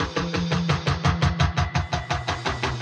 Index of /musicradar/rhythmic-inspiration-samples/85bpm
RI_DelayStack_85-07.wav